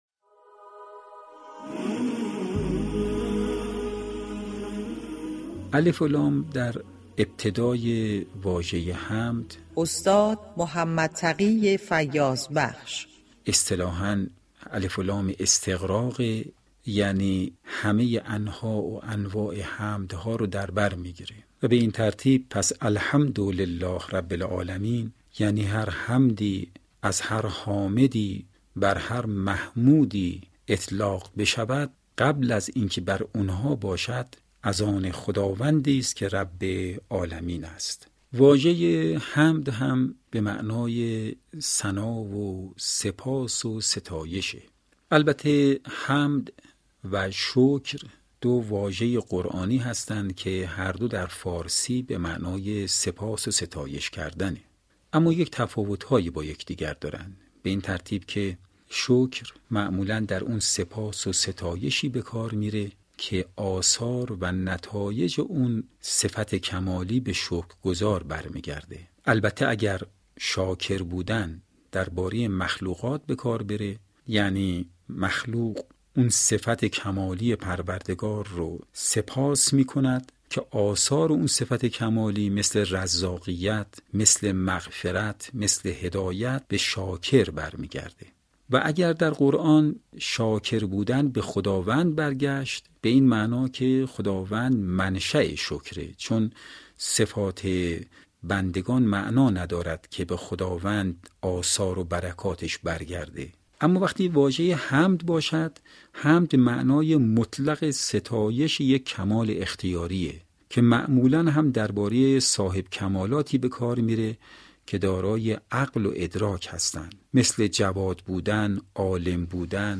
رادیو قرآن با یازدهمین قسمت از برنامه گفتارمحور «ترجمان وحی» به سراغ واژه‌ای می‌رود كه هم در زبان قرآن مكرر تكرار شده و هم عنوان یكی از مهم‌ترین سوره‌های كتاب وحی را بر خود دارد: «حمد»؛ واژه‌ای كه نشان می‌دهد مسیر بندگی به ستایش آگاهانه خداوند آغاز می‌شود.